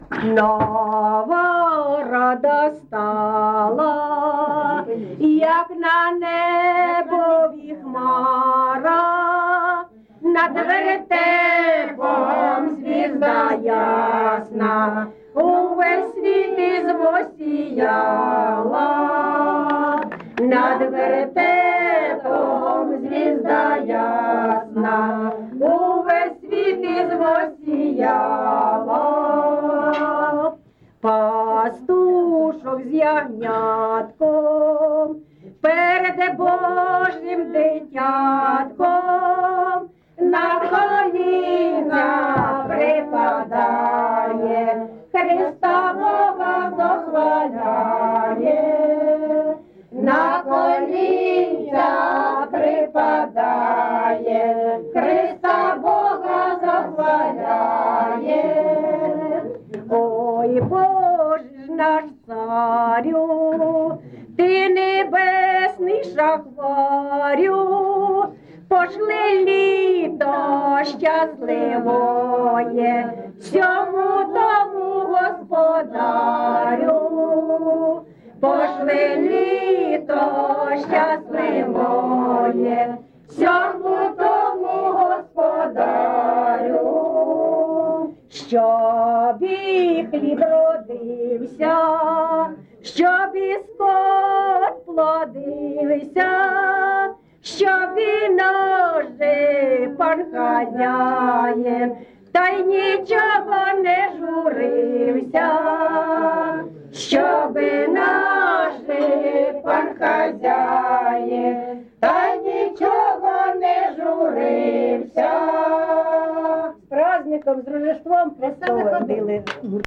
ЖанрКолядки
Місце записус. Шарівка, Валківський район, Харківська обл., Україна, Слобожанщина